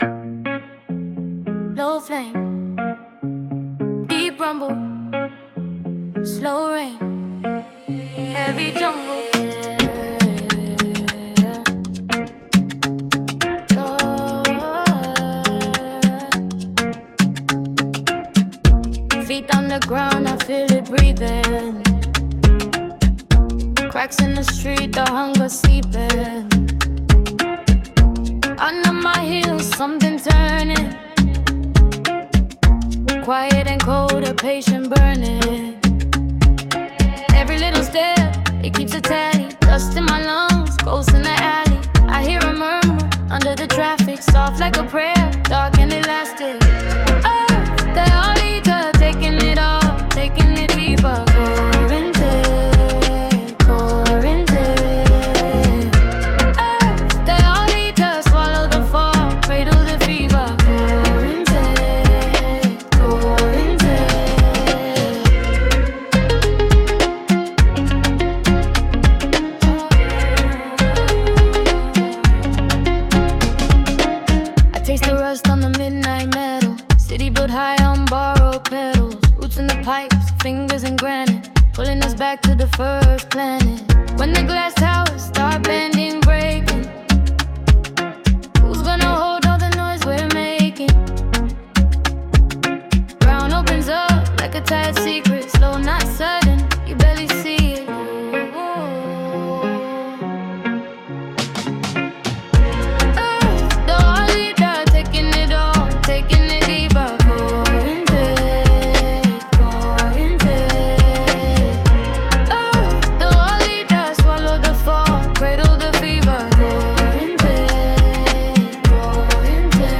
Low-frequency emergence